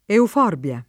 vai all'elenco alfabetico delle voci ingrandisci il carattere 100% rimpicciolisci il carattere stampa invia tramite posta elettronica codividi su Facebook euforbia [ euf 0 rb L a ] s. f. («pianta») — cfr. euforbio